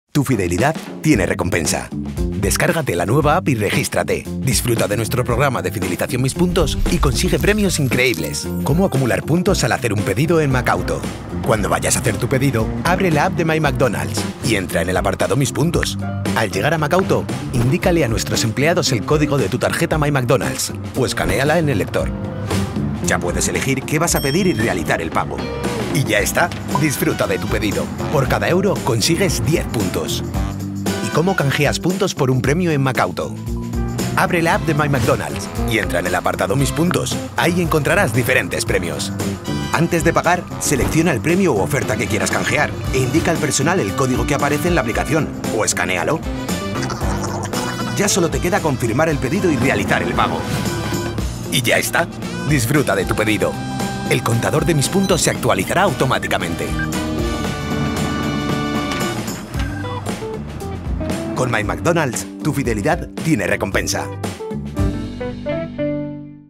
ELearning -